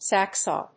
/ˈsæksɔːl(米国英語)/